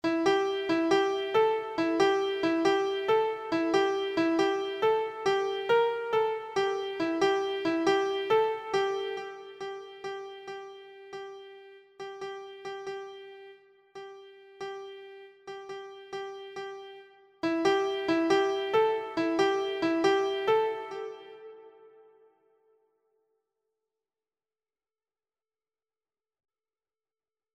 (Folk song)